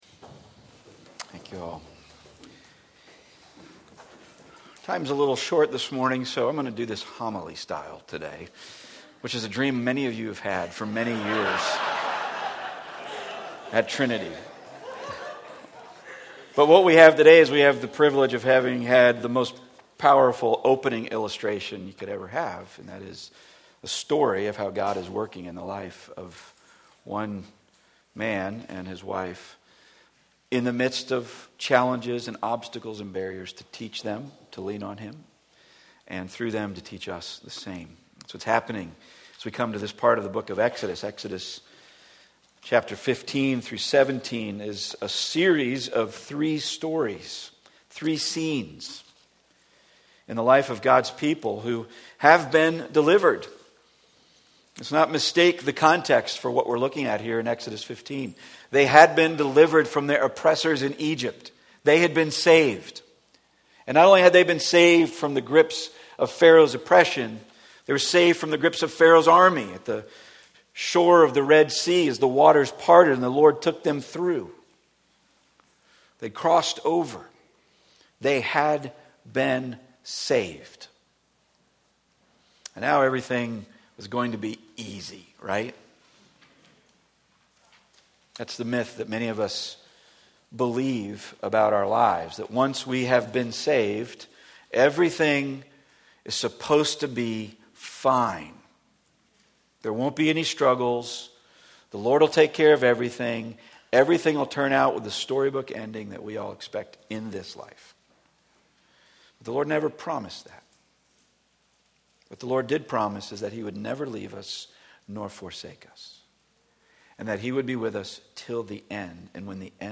Passage: Exodus 15:22-17:7 Service Type: Weekly Sunday